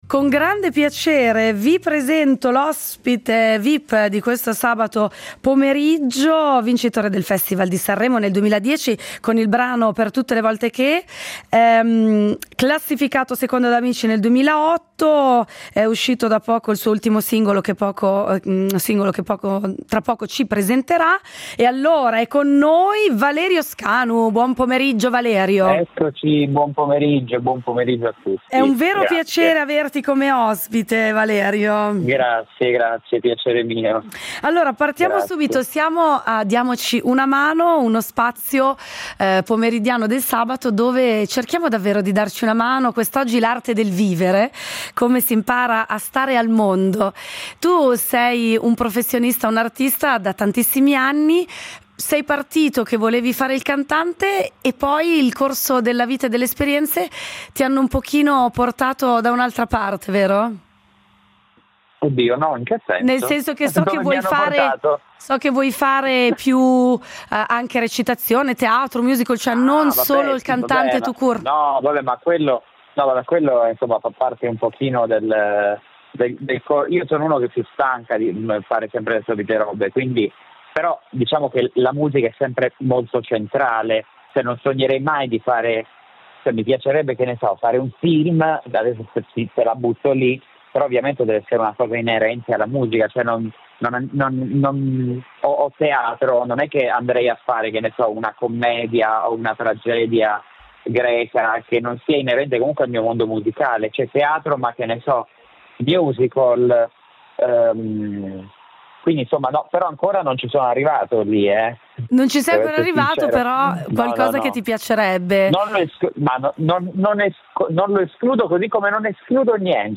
Incontro con Valerio Scanu, cantante e personaggio televisivo italiano